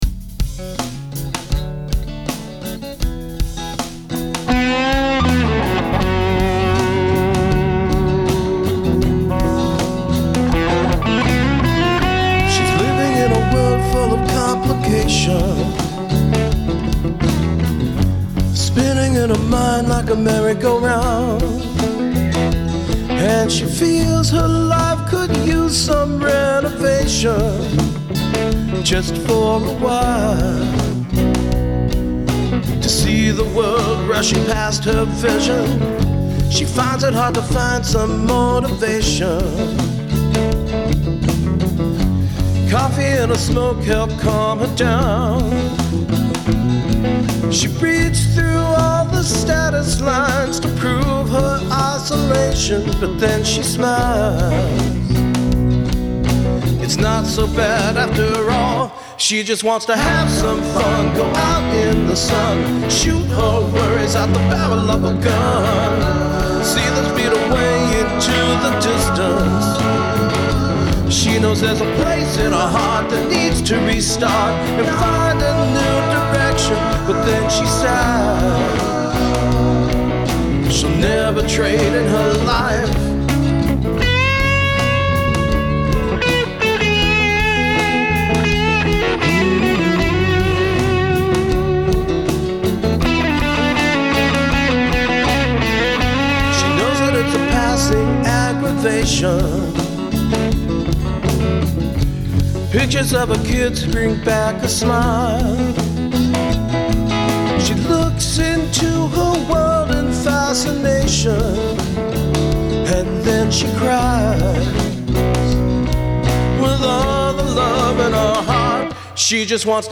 But I updated the song today for Mothers Day, removing the piano, and adding electric guitars and backup vocals. Then I completely re-mixed the entire song to make the sound a bit fatter without losing dynamics.
You might’ve noticed that after the line in the first verse, “spinning in her mind…” there was a little, distant shriek.
For the electric guitar parts, I kept things VERY simple. There’s a basic rhythm track where I’m comping some two-string chords underneath the verses.
All the dirt is coming from the amp.
The Tatooed Lady is providing all the grit and grime, plus lots of sustain.